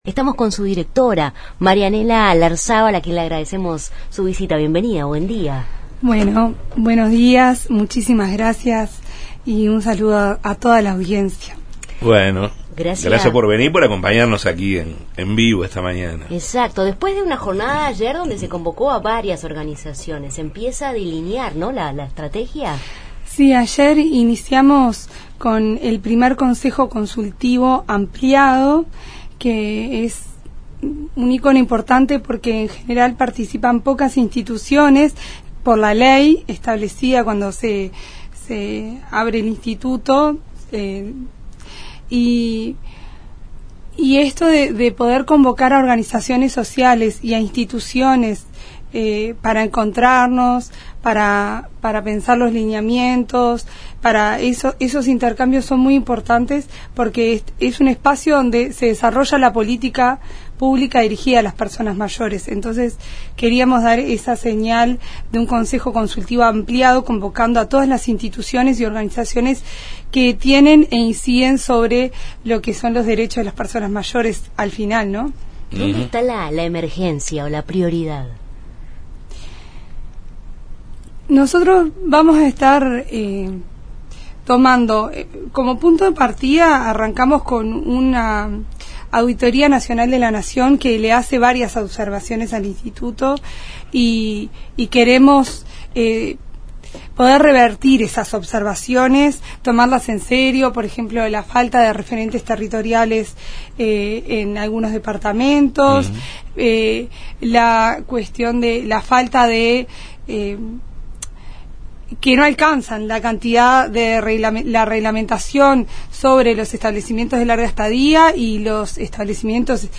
Entrevista a Marianela Larzábal, directora del Instituto Nacional del Mayor (Inmayores), del Ministerio de Desarrollo Social (Mides)